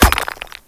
concrete_impact_bullet2.wav